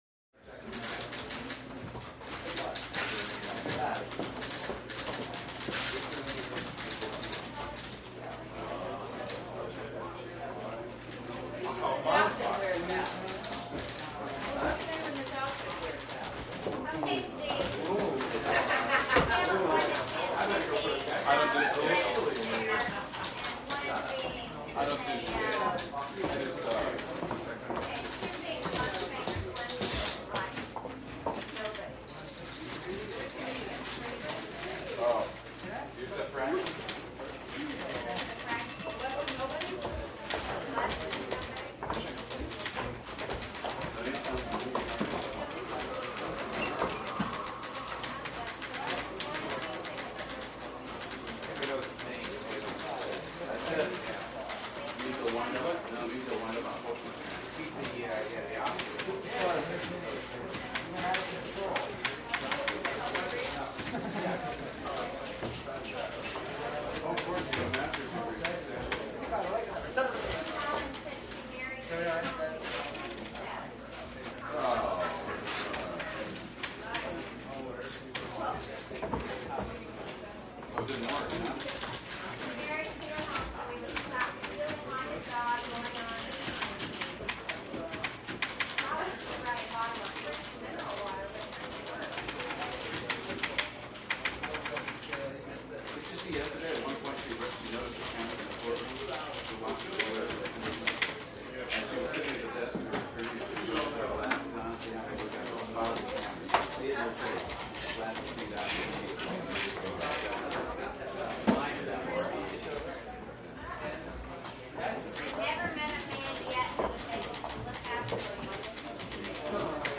Hallway - Large And Busy Office